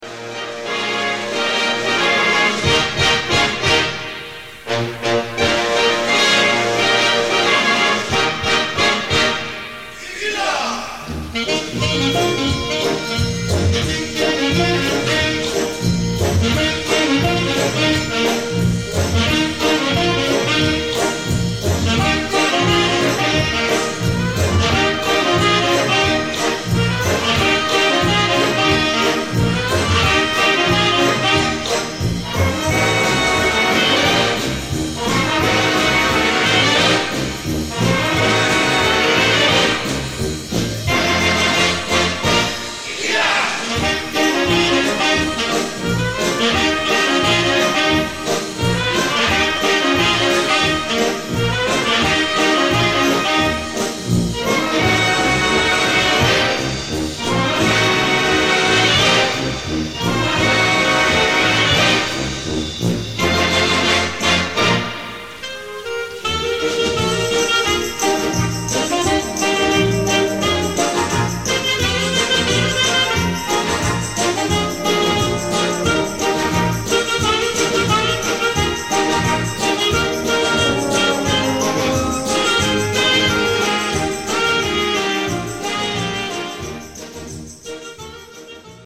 Gattung: Solo für vier Saxophone und Blasorchester
Besetzung: Blasorchester